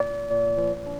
keys_90.wav